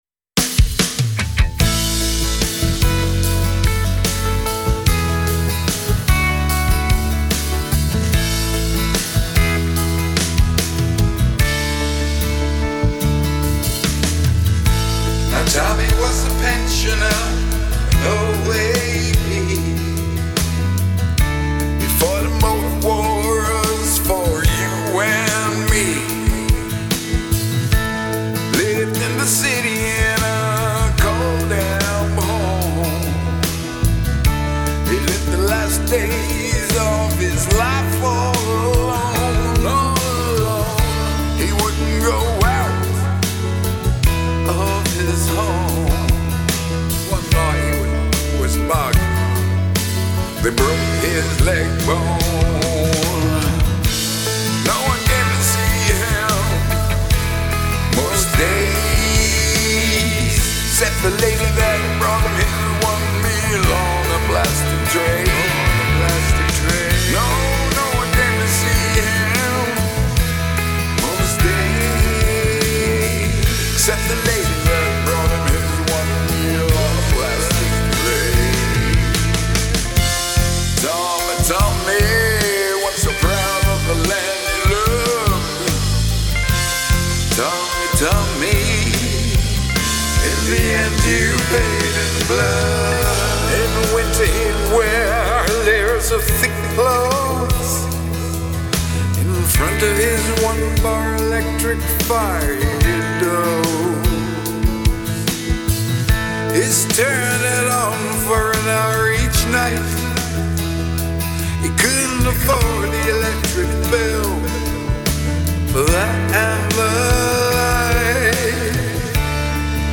Rock songs